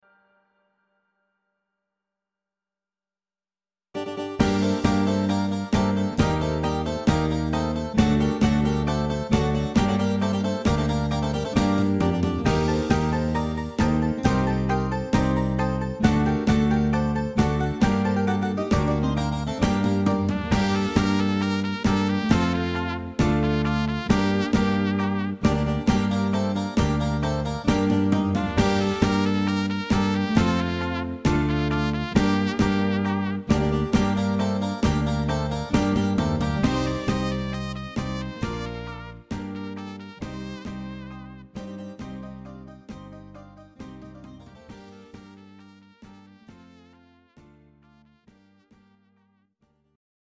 Greek Zeimpekiko